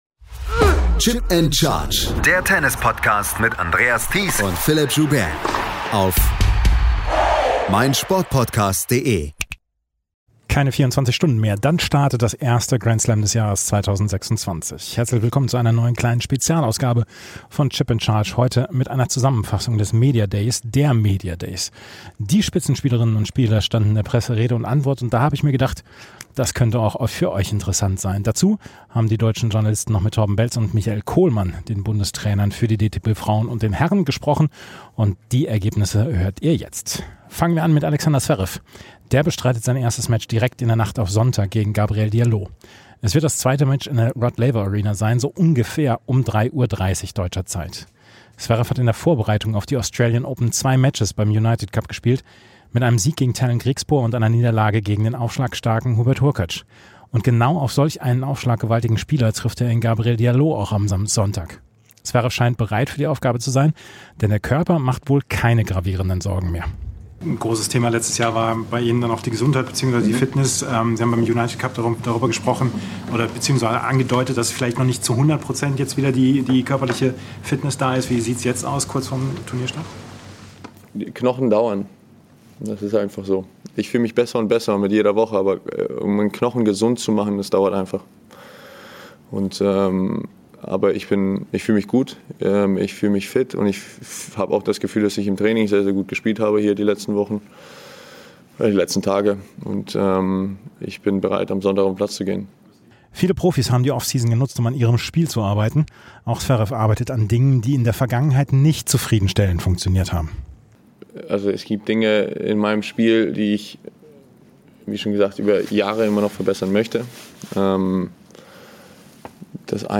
Mit dabei: Stimmen von Alexander Zverev, Eva Lys, Stan Wawrinka, Alex de Minaur, Michael Kohlmann, Carlos Alcaraz und Jan-Lennard Struff.